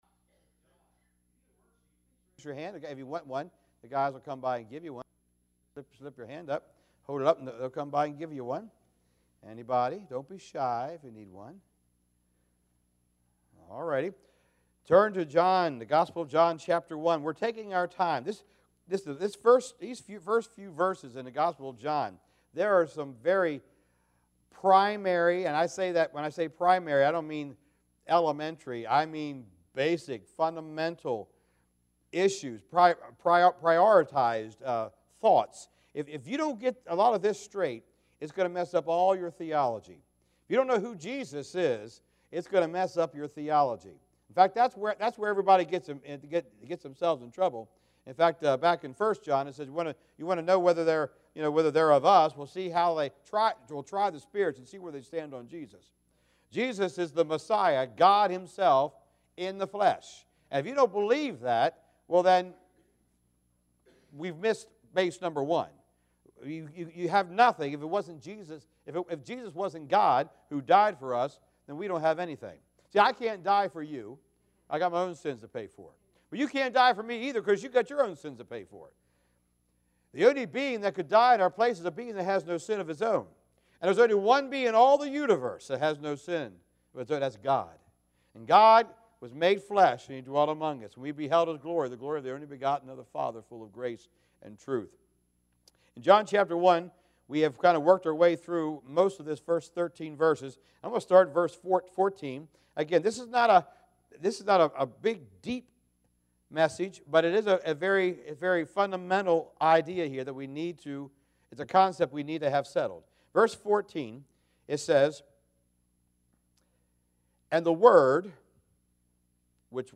Passage: John 1:14-18 Service Type: Wednesday Evening